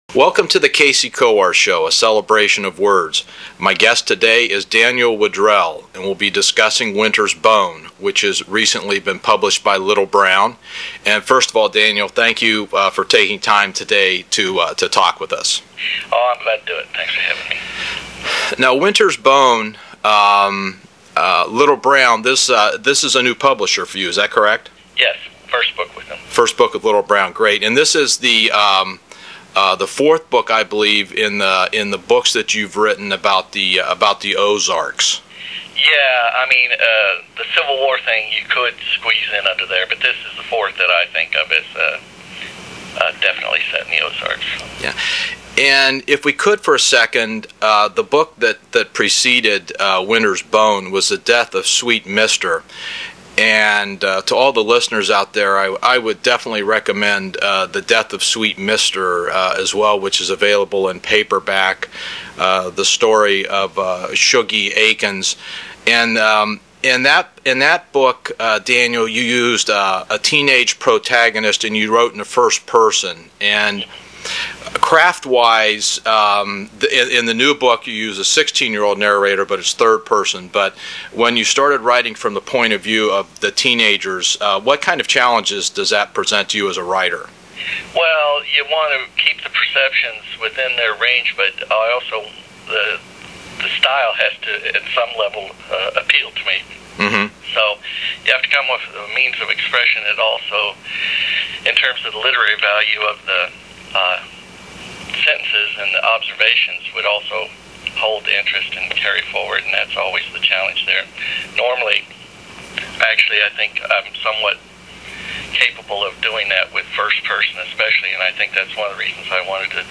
I encourage you to listen to our August, 2006 interview prior to the awards show.